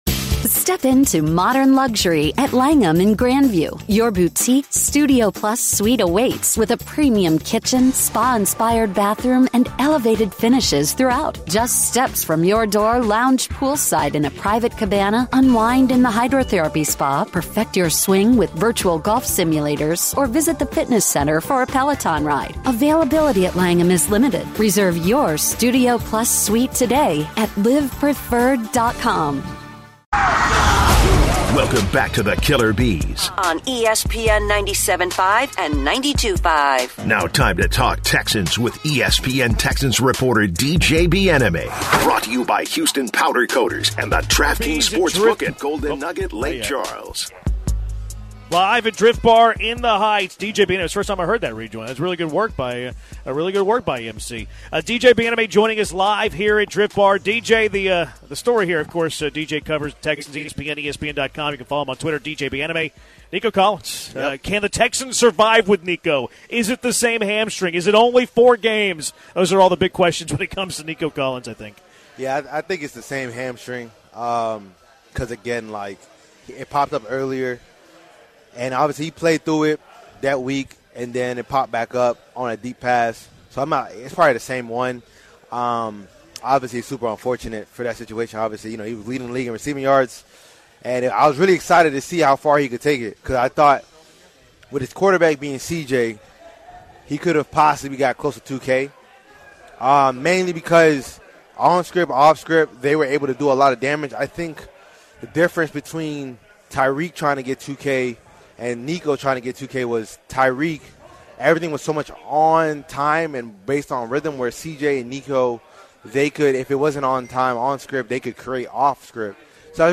10/11 Hour 3- Live from Drift Bar